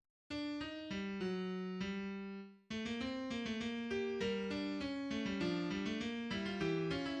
Example of a tonal answer in J.S. Bach's Fugue No. 16 in G minor, BWV 861, from the Well-Tempered Clavier, Book 1. The first note of the subject, D (in red), is a prominent dominant note, demanding that the first note of the answer (in blue) sound as the tonic, G.